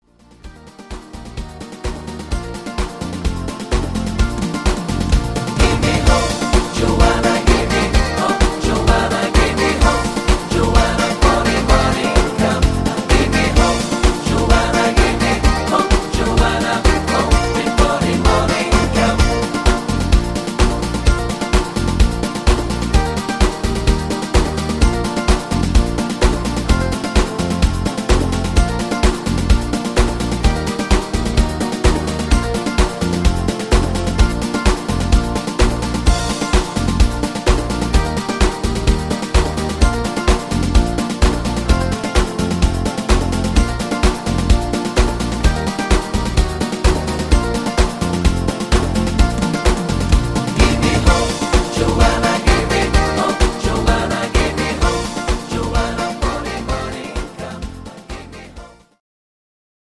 Rhythmus  Disco Mambo
Art  Englisch, Oldies, Pop